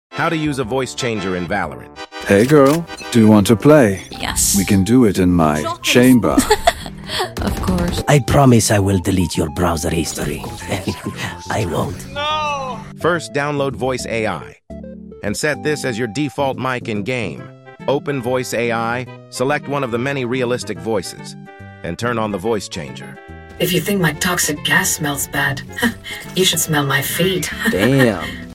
Using valorant to troll people in voice chat